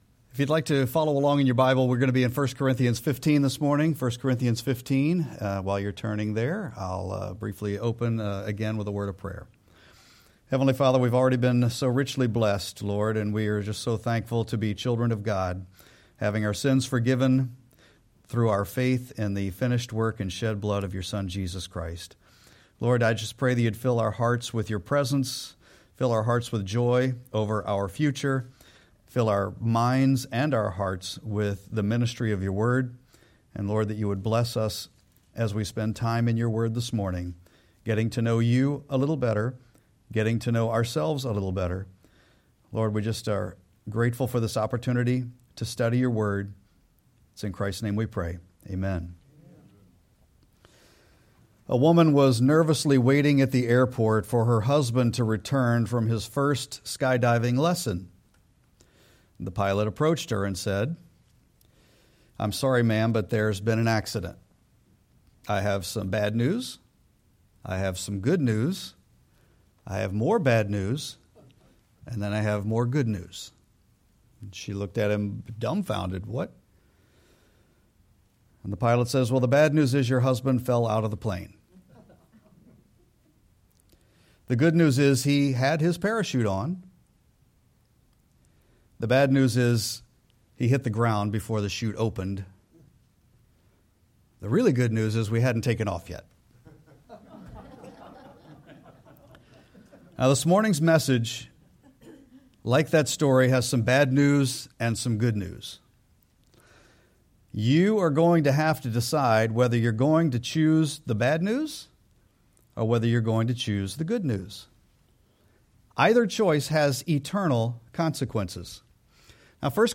Sermon-4-5-26.mp3